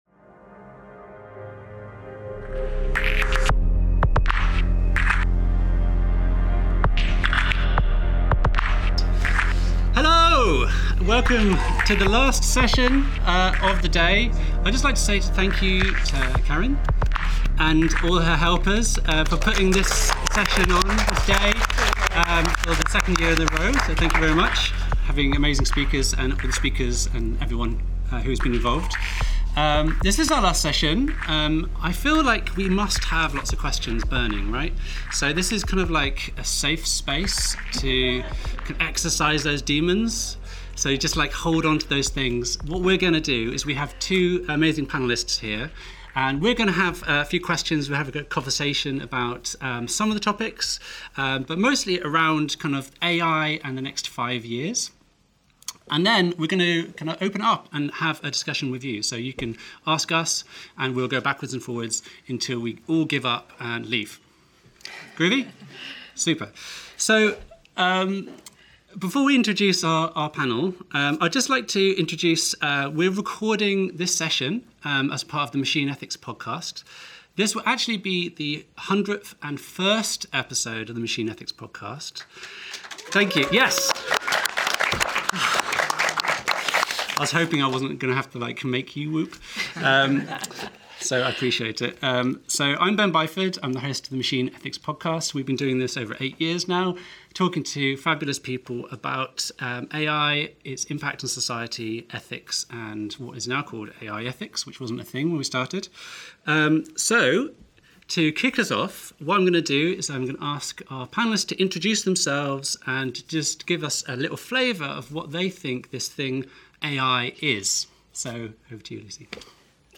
Discourse on AI Ethics. News, explanation and Interviews with academics, authors, business leaders, creatives and engineers on the subject of autonomous algorithms, artificial intelligence, responsible AI, machine learning, AGI, technology ethics, conciousness, philosophy and more.